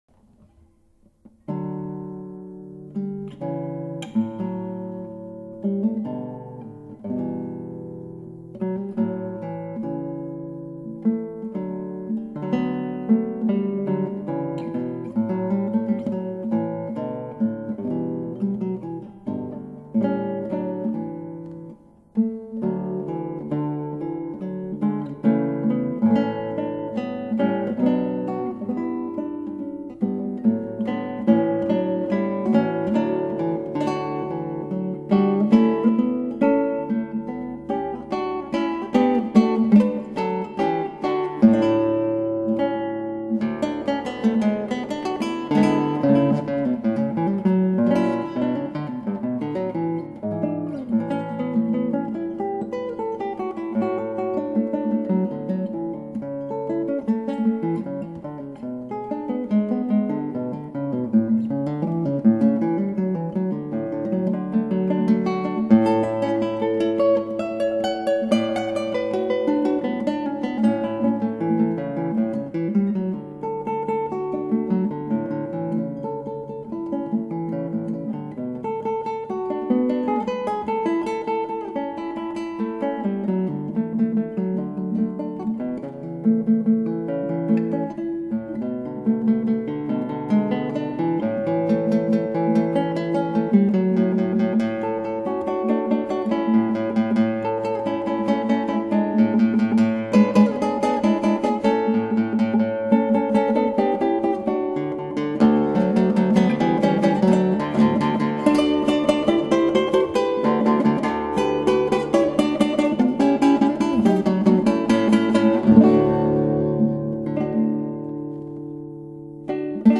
クラシックギター　ストリーミング　コンサート
トリハダたっちゃってさ・・・・・、もっと抑えた表現しなきゃなんないのに、音量全開状態になってしまうんです。